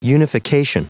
Prononciation du mot unification en anglais (fichier audio)